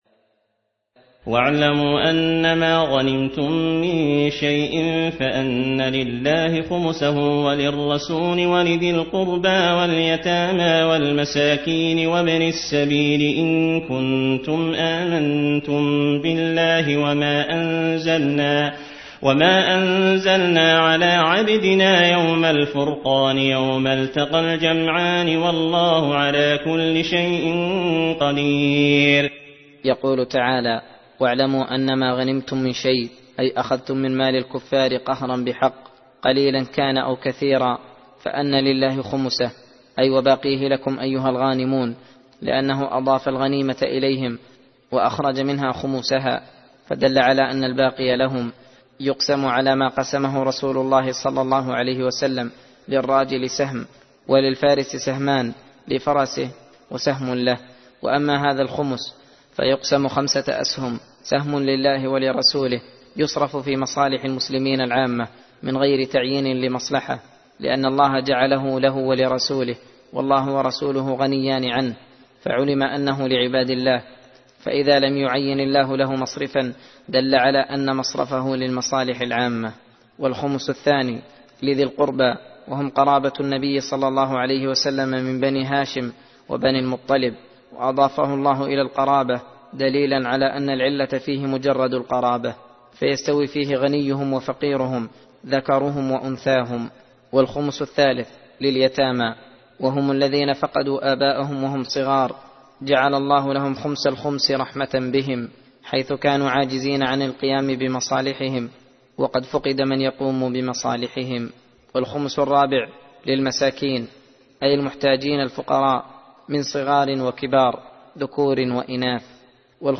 درس (14) : تفسير سورة الأنفال (41-58)